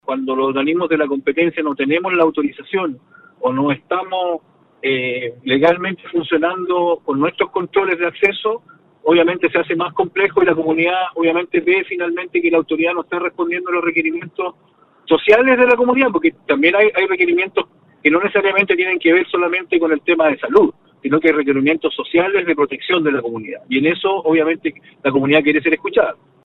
El alcalde de Huasco, Rodrigo Loyola conversó con Nostálgica, donde mencionó que luego de parlamentar con los manifestantes, estos le señalaron que realizaron el bloqueo con la intensión de evitar el ingreso de personas provenientes desde Vallenar o desde fuera de la región: